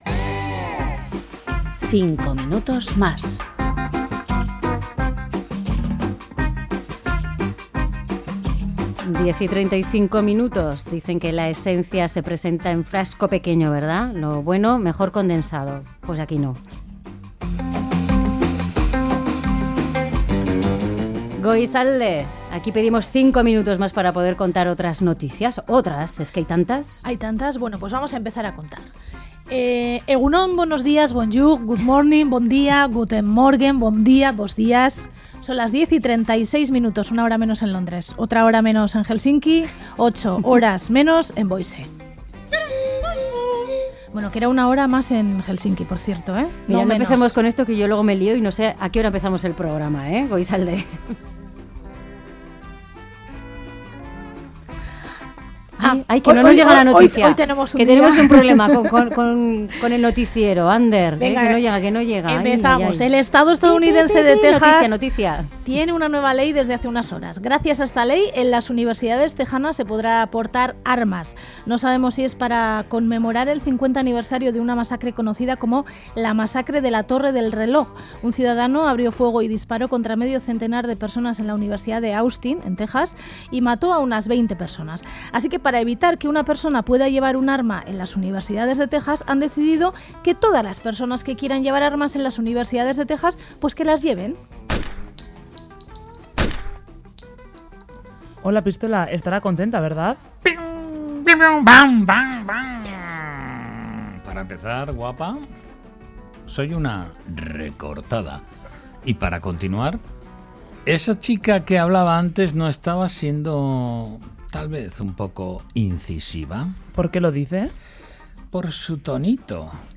5 minutos más| Informativo| RADIO EUSKADI